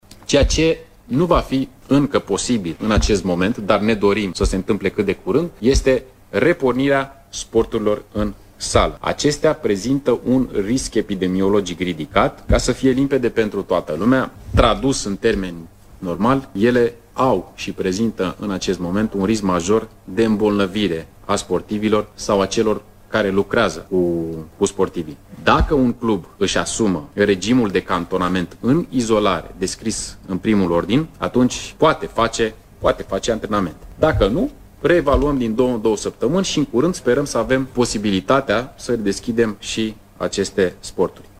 Setul de condiţii a fost prezentat, astăzi, într-o conferinţă de presă, de ministrul Tineretului şi Sportului, Ionuţ Stroe.